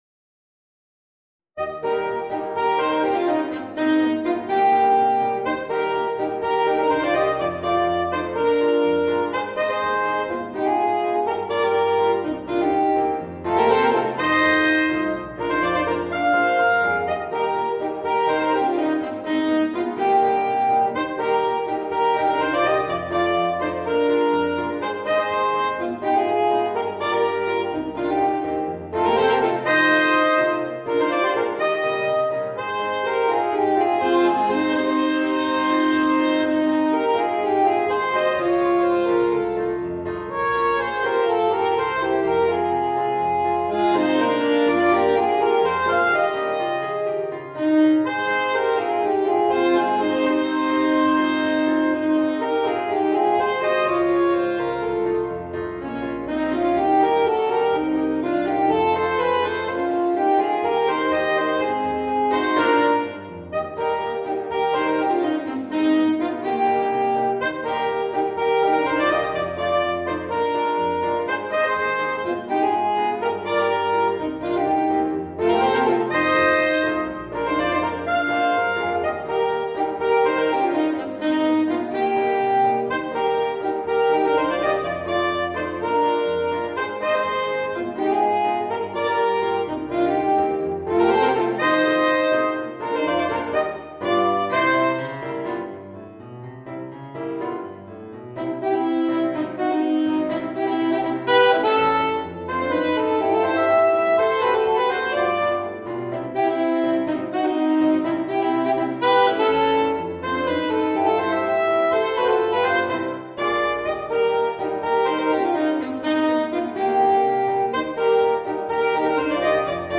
Voicing: 2 Alto Saxophones and Piano